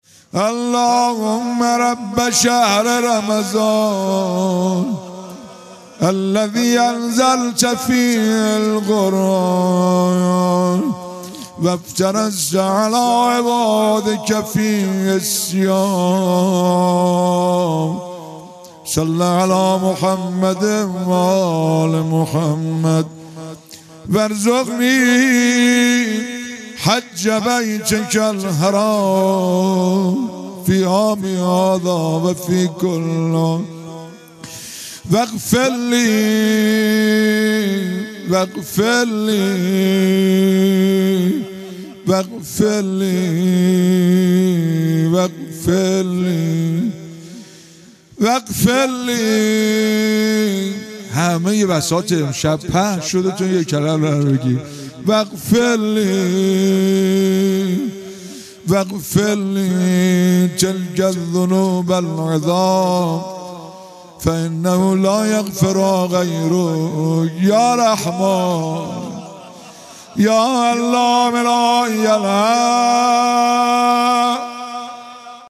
شب پنجم ماه مبارک رمضان با نوای گرم
در حرم حضرت فاطمه معصومه(س)